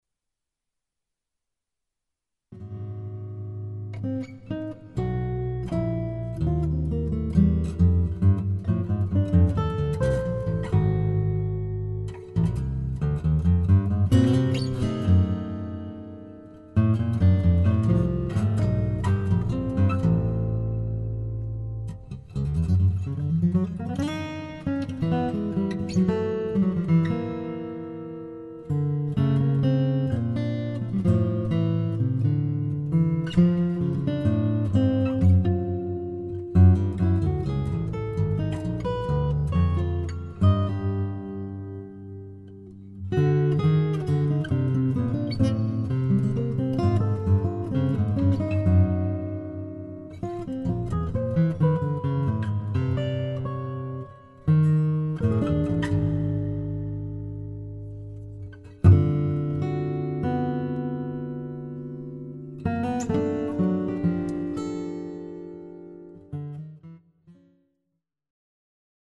for 6-string and extended baritone guitar duet